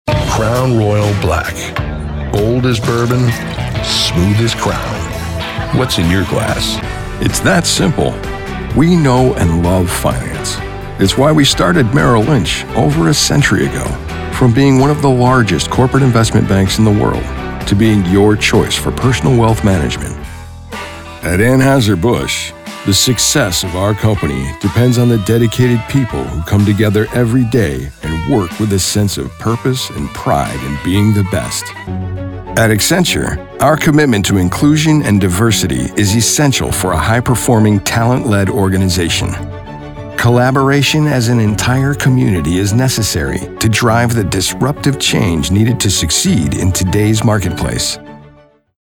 SOURCE-CONNECT Certified US MALE VOICOVER with HOME STUDIO
• BOOTH: Whisper Room, acoustically-treated
smooth
MIX11-smooth.mp3